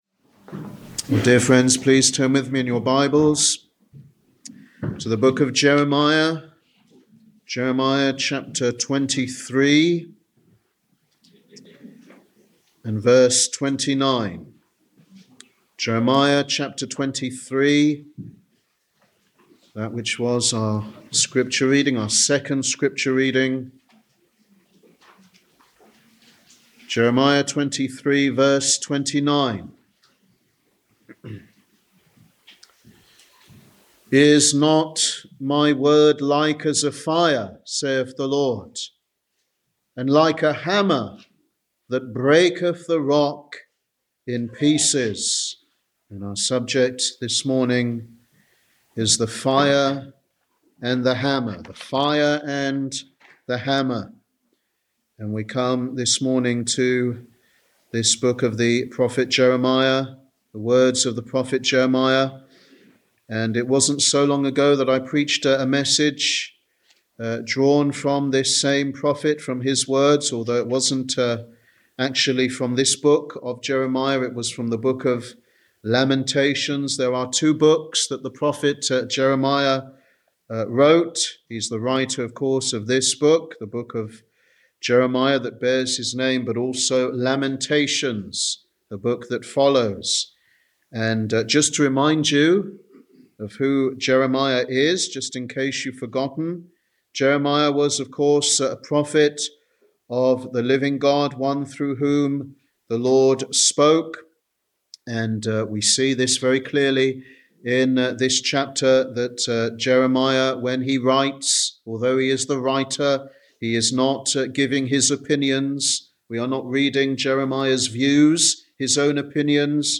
Sunday Evangelistic Service
Sermon